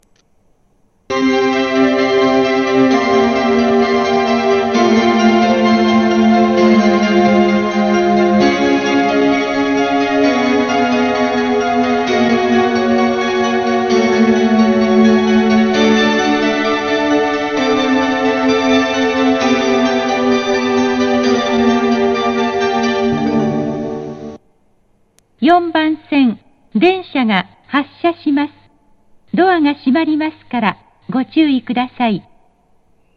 接近放送 「Verde Rayo V2」です。
●スピーカー：National天井丸型
●音質：良